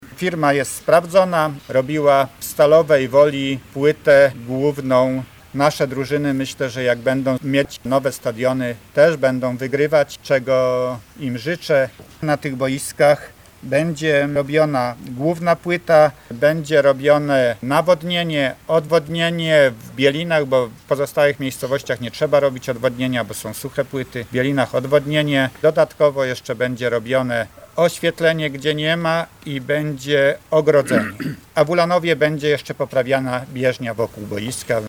Mówi burmistrz Ulanowa Stanisław Garbacz